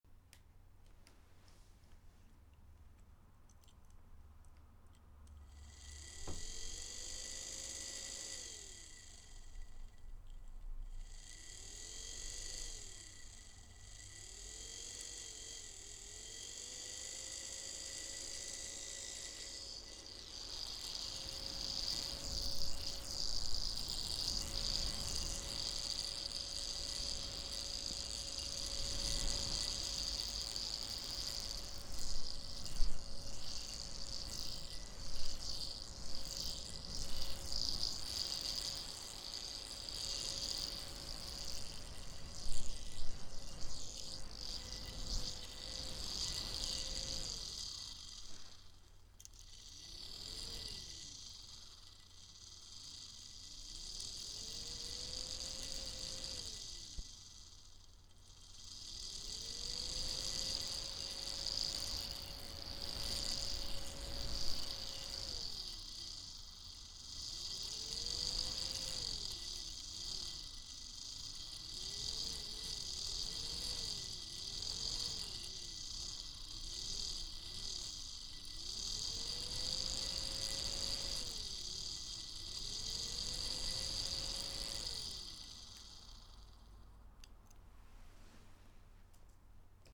Fan-Noise-08.mp3